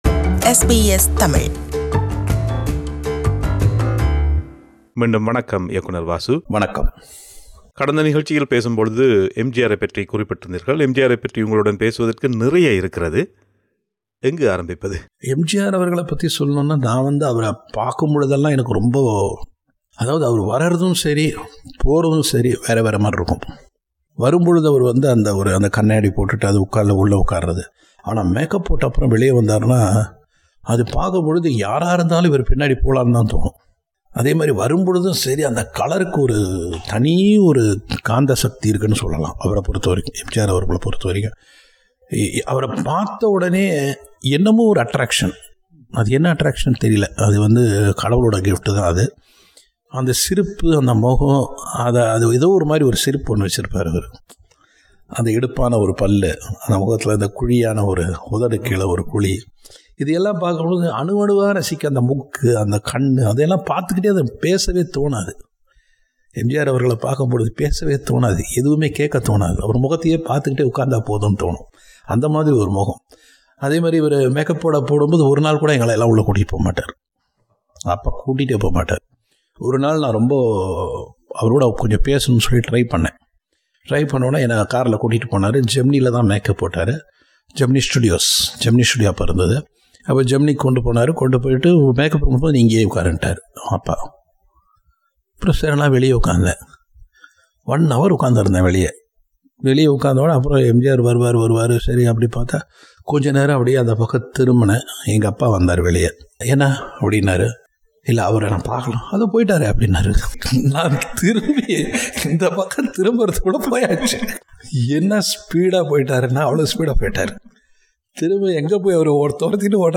In this third part of the interview, P Vasu talks about his re-collection of MGR and the movie he is making in which MGR is the hero.